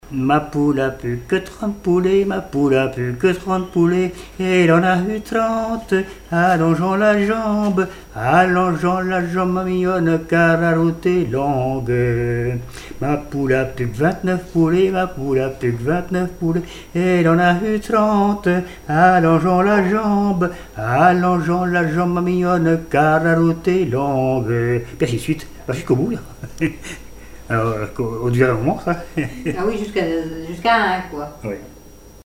Localisation Flocellière (La)
Genre énumérative
chansons et témoignages
Pièce musicale inédite